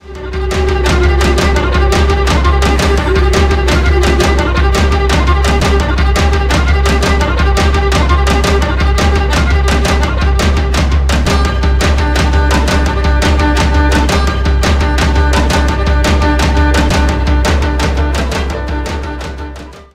RainH1.wav